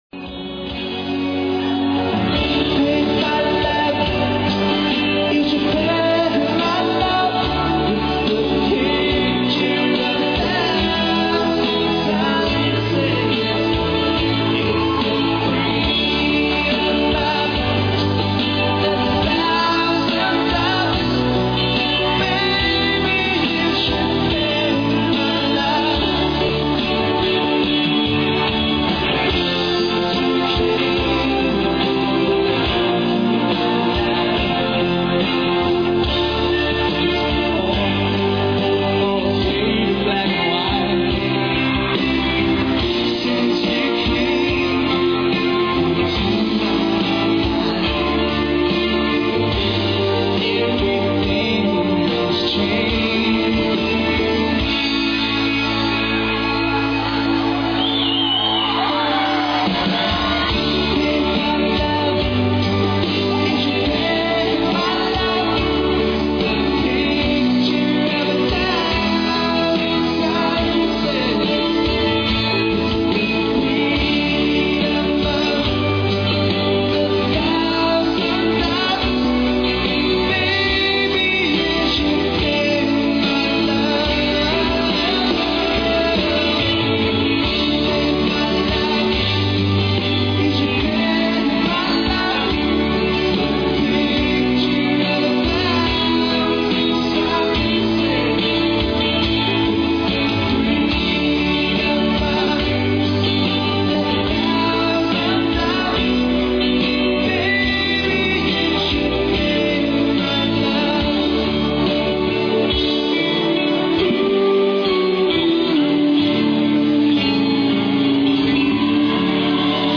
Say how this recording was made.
live recorded at Nordals Musikfestival 1997.